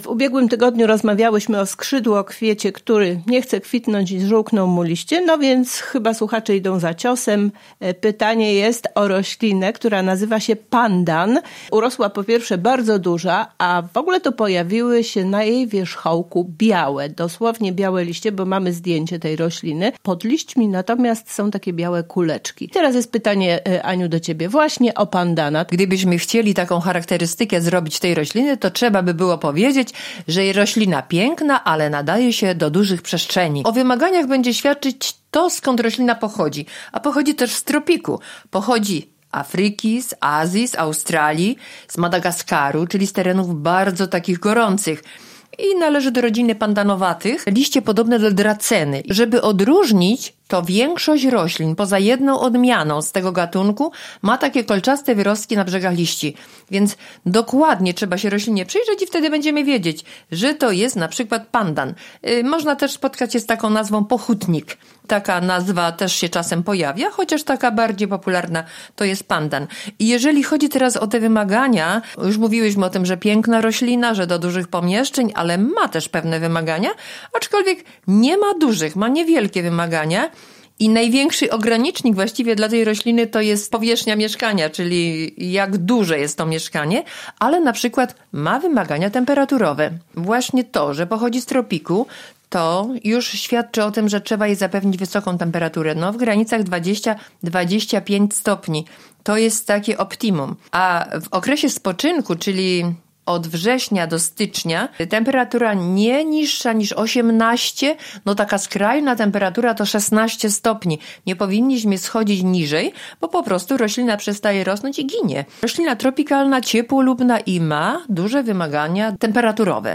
O szczegółach pielęgnacji w naszej rozmowie.